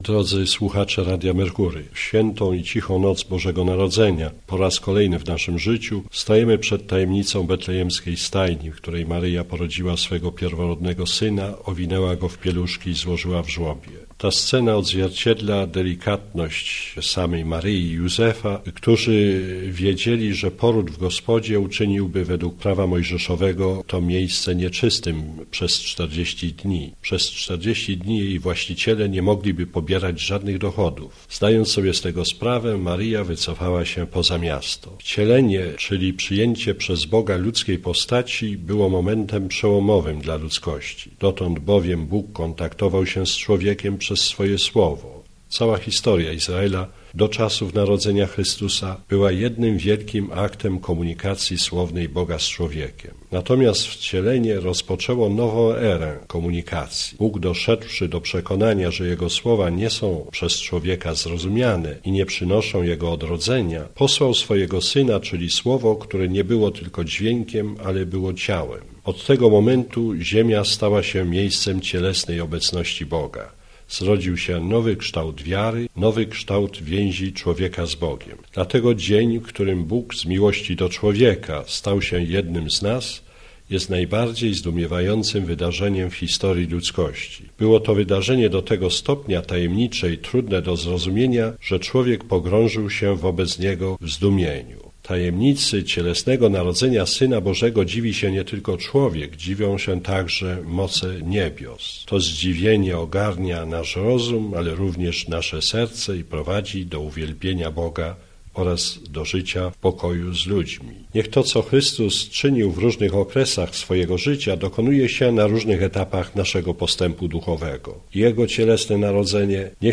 Życzenia od arcybiskupa Gądeckiego
Podnieś rękę Boże Dziecię i błogosław każdemu z nas, naszym rodzinom, całej Naszej Ojczyźnie i całemu światu na drogę odważnej wiary zdolnej zwyciężać wszelkie kryzysy - powiedział Arcybiskup Stanisław Gądecki składając życzenia świąteczne Słuchaczom Radia Merkury.